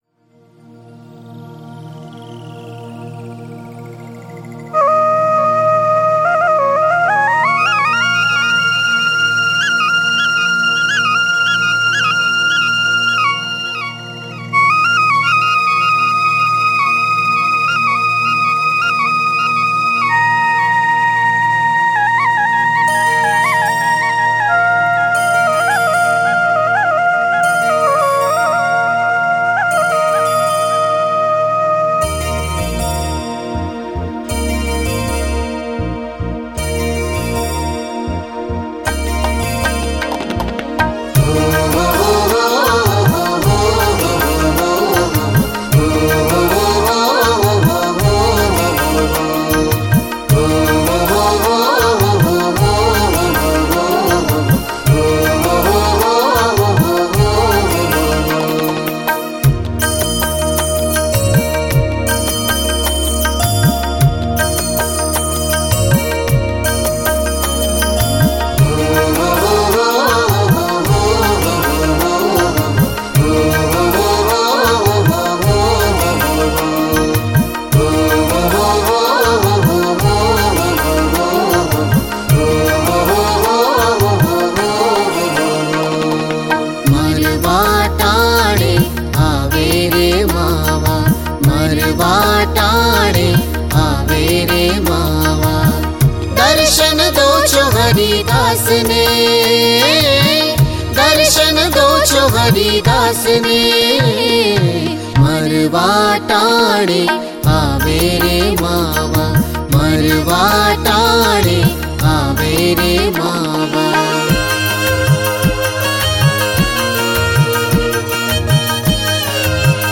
🎵 Marvā Ṭāṇe / મરવા ટાણે – રાગ : મેઘ